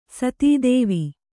♪ satī dēvi